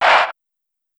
Metro Dry Vox.wav